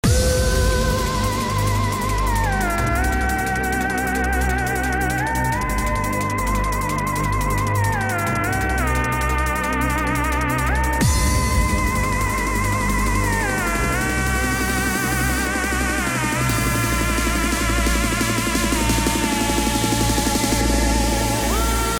I can hear there are 2 pads in the background, but the main melody stands out, and just sounds like it's a tiny bit detuned, but it isn't... If I'm right, it goes like this: C1 A# G F G A# C1 A# G F G D# A# C1 A# G F G F D# F D# D A# View attachment MeloSample.mp3 Could someone point me in the right direction?